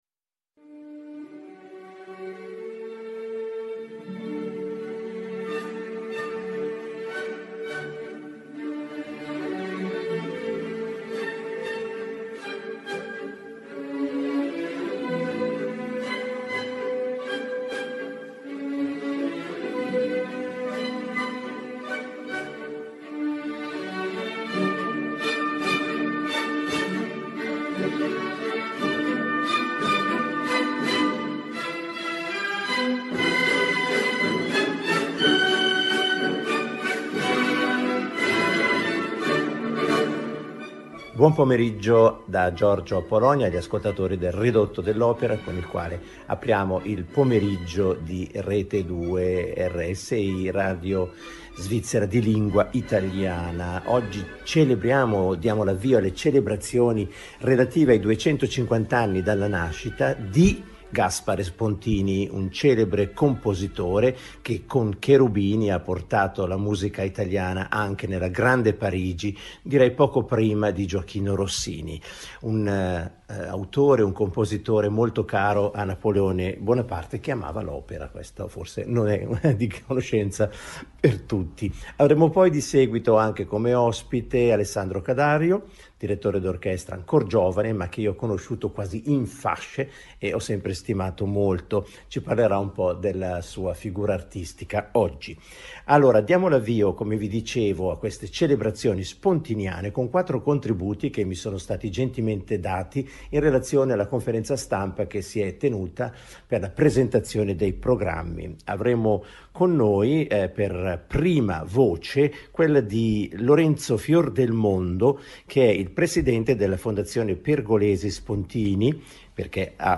Un’intervista